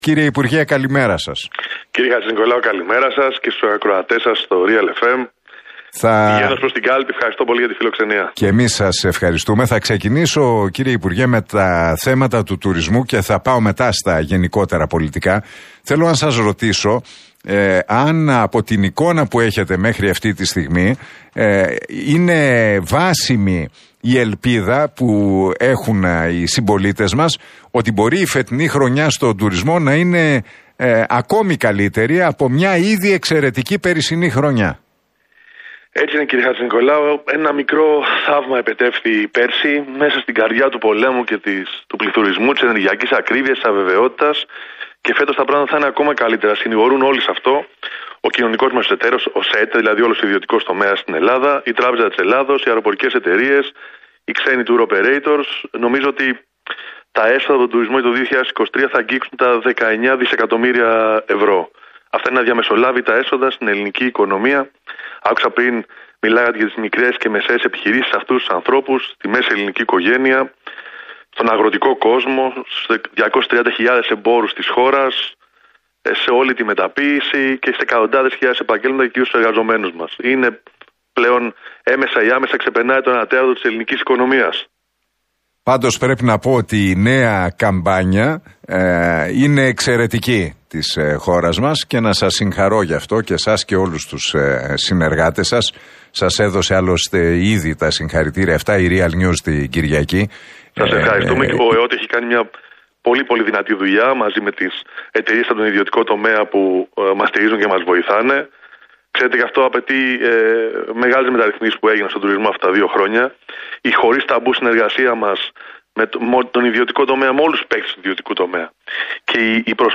Ο υπουργός Τουρισμού και υποψήφιος με τη ΝΔ στην Α Αθηνών, Βασίλης Κικίλιας, μίλησε στην εκπομπή του Νίκου Χατζηνικολάου στον Realfm 97,8.